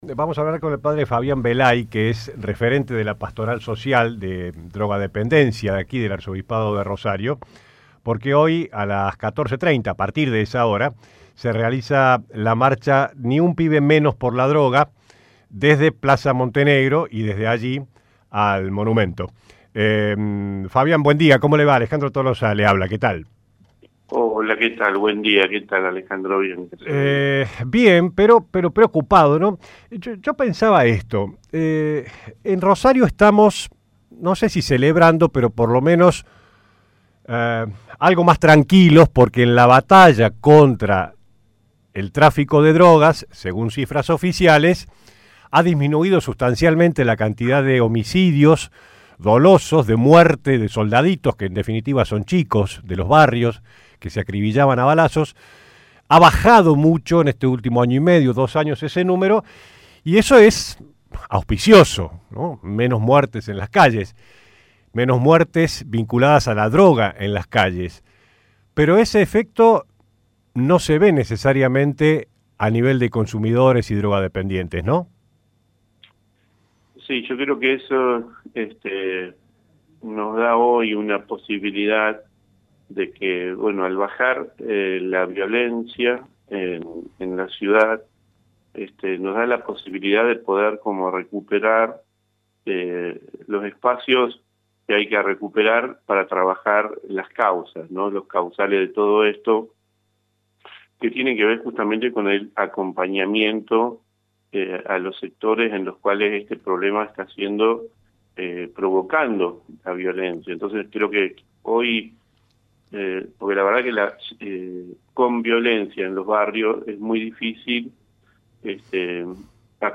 habló en el programa La Barra de Casal